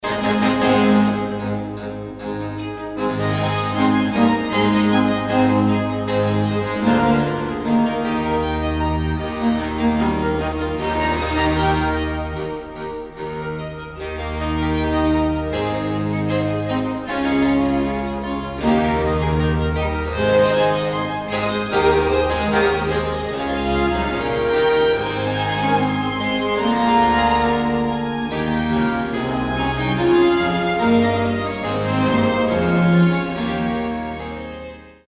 Baroque chamber ensembles
suite for violin, 2 violas & continuo in A major